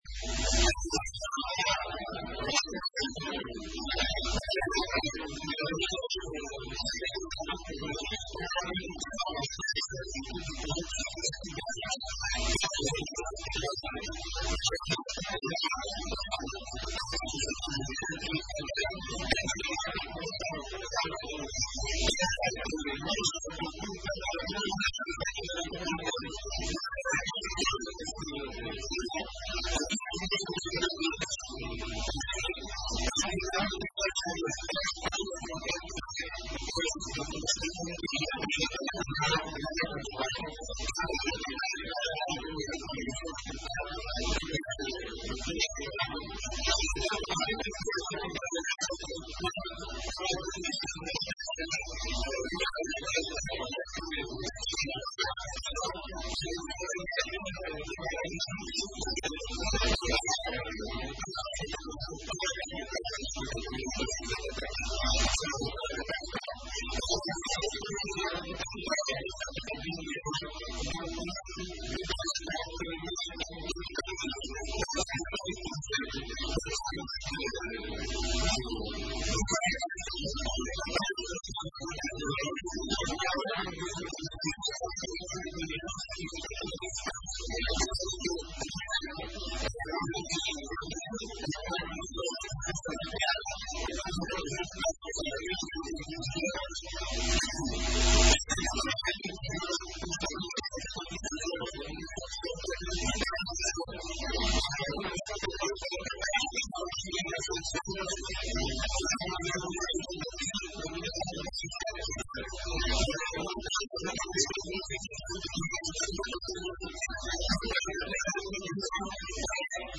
Isso mesmo explicou hoje, no parlamento açoriano, o Secretário Regional da Economia, durante uma sessão de perguntas ao Governo, sobre diversos temas.